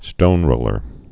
(stōnrōlər)